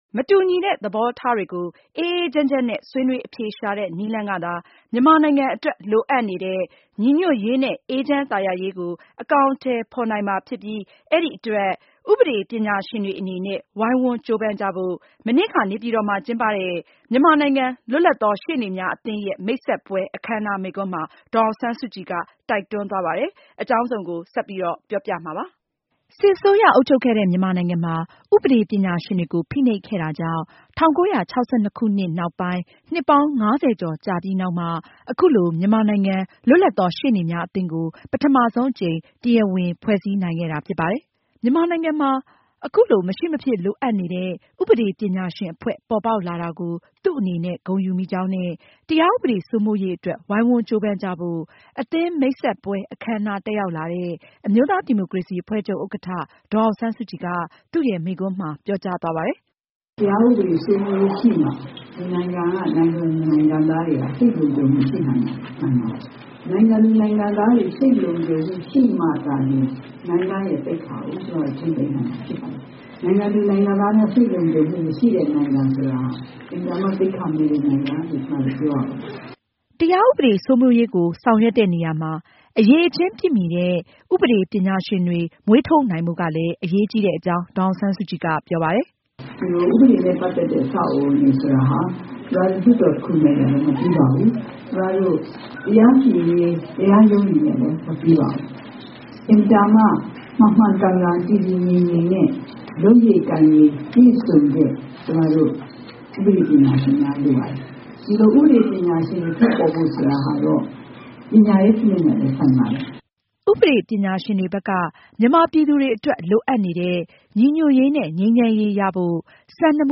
DASSK's speech at the meeting of ILAM